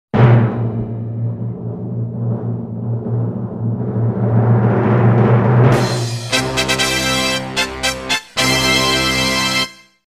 دانلود آهنگ تبل 3 از افکت صوتی اشیاء
جلوه های صوتی